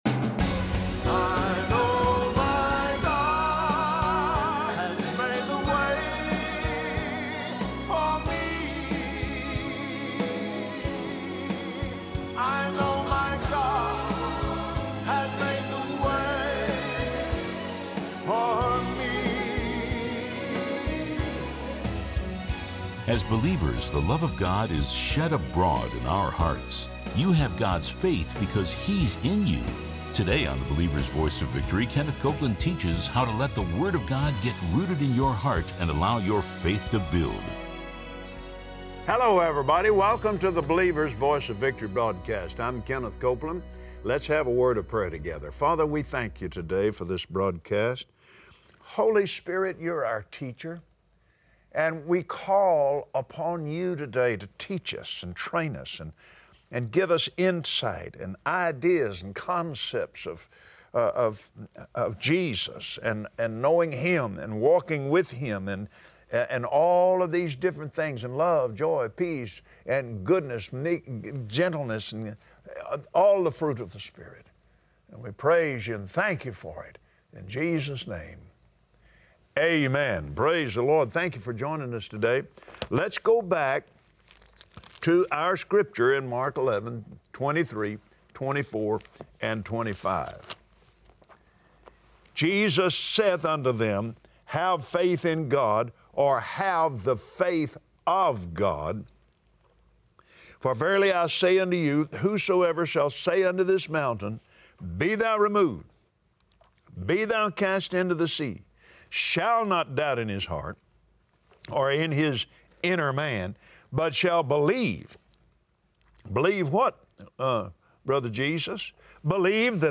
Believers Voice of Victory Audio Broadcast for Tuesday 08/08/2017 God is Love, and Love is God! Watch Kenneth Copeland on Believer’s Voice of Victory share how a revelation of God’s love is the foundation of faith for all things.